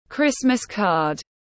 Thiệp Giáng sinh tiếng anh gọi là Christmas Card, phiên âm tiếng anh đọc là /ˈkrɪs.məs ˌkɑːd/
Christmas Card /ˈkrɪs.məs ˌkɑːd/